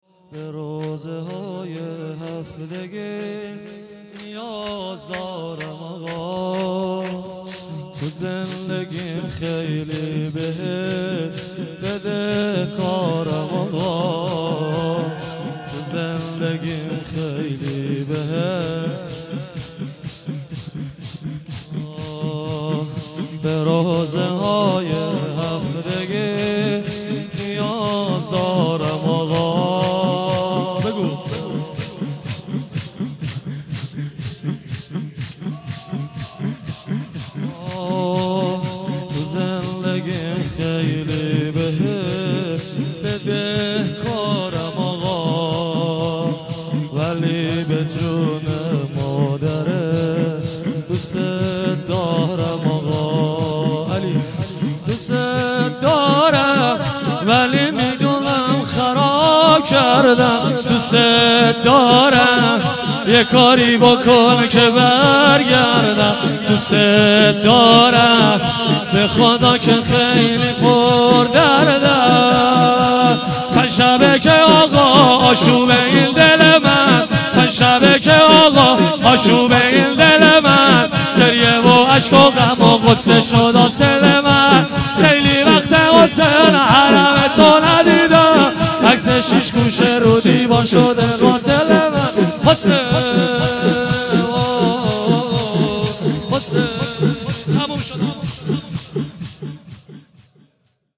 0 0 شور